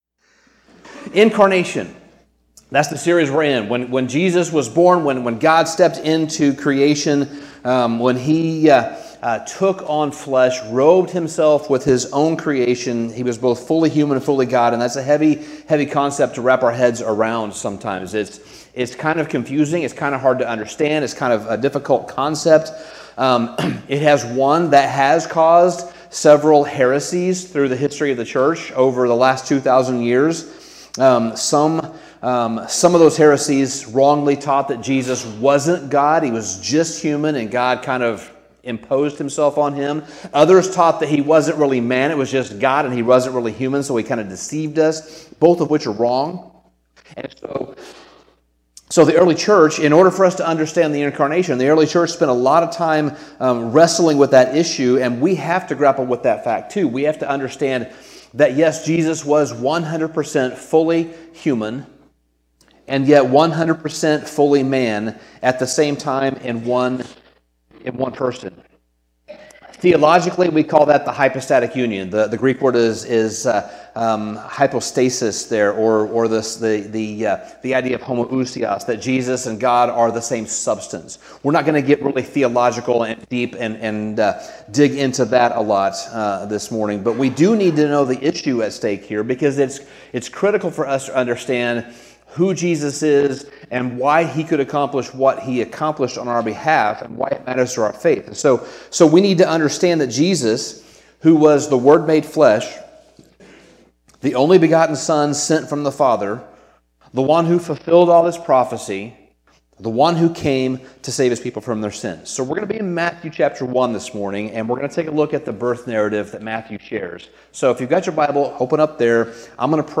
Sermon Summary Matthew 1 beautifully presents the dual natures of Jesus – His full humanity and complete divinity – offering us a profound glimpse into the miracle of the incarnation.